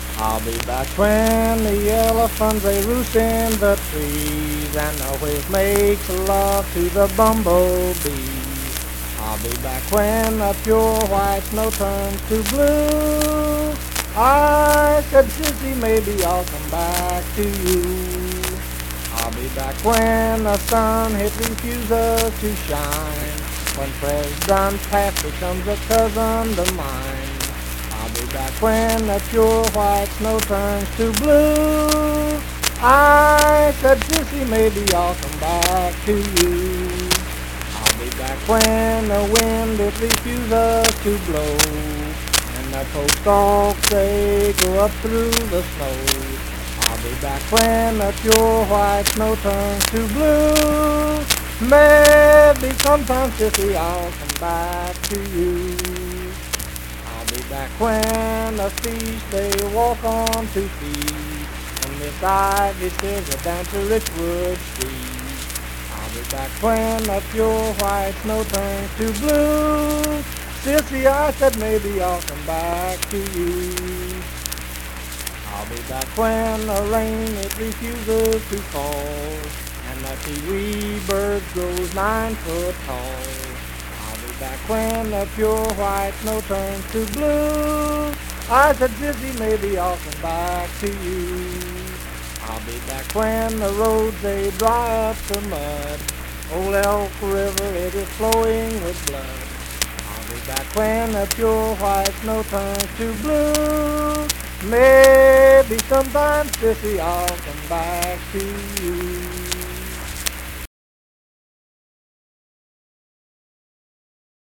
Unaccompanied vocal music
Voice (sung)
Saint Marys (W. Va.), Pleasants County (W. Va.)